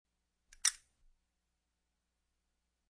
Descarga de Sonidos mp3 Gratis: percusion 14.